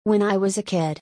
[when I]「フェンアイ→フェナイ」n+Iで「ナイ」の発音に変化している